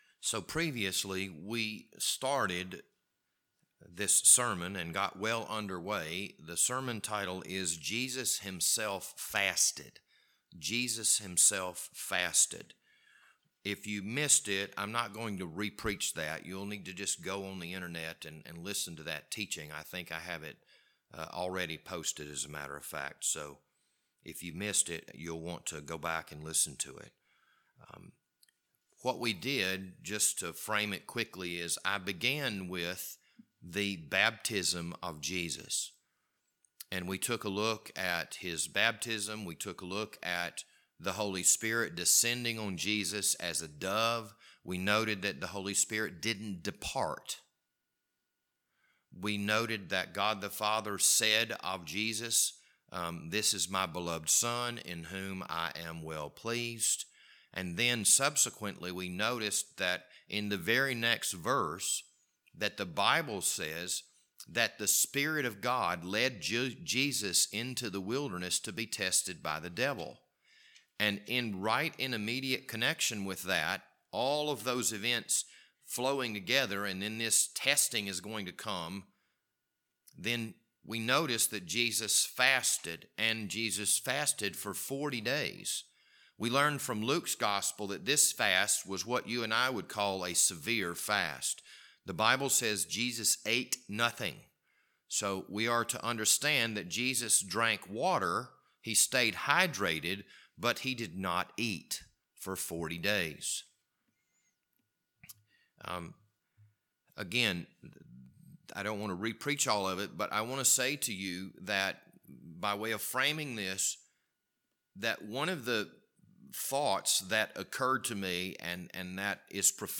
This Wednesday evening Bible study was recorded on March 2nd, 2022.